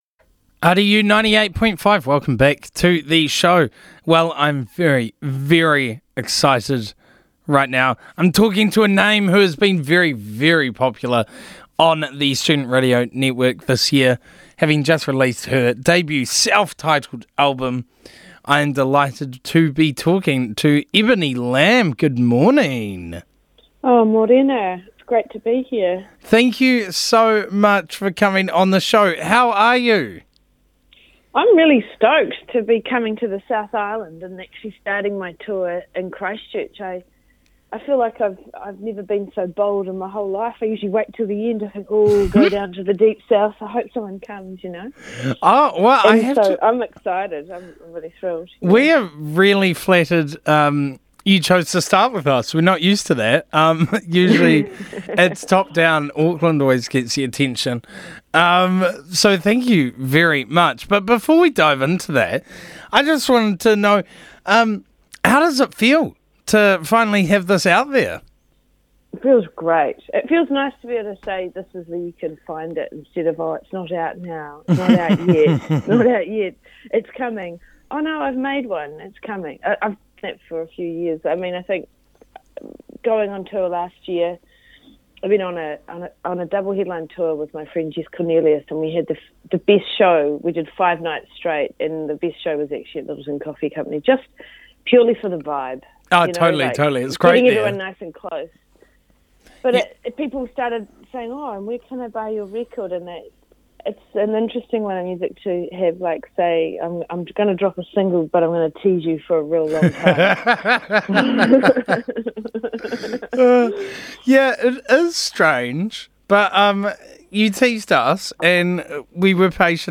She dials into RDU’s Burnt Breakfast to talk about the album, the journey of making it, the amazing names who played a part in it, and how it feels to finally have a body of work out there.